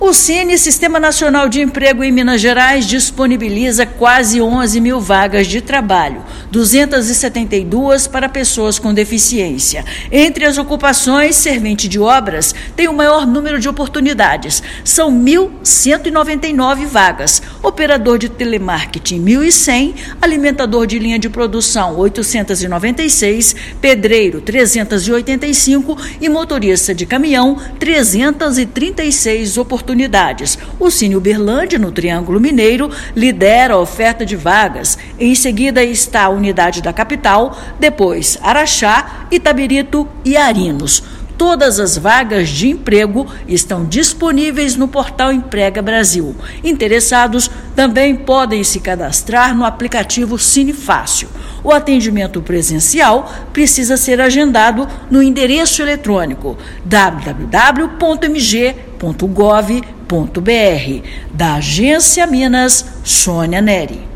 [RÁDIO] Sine-MG disponibiliza mais de 10,9 mil vagas de emprego
Mais de 10,9 mil vagas de trabalho estão disponíveis em unidades do Sistema Nacional de Emprego (Sine-MG) nesta segunda-feira (15/5). Ouça matéria de rádio.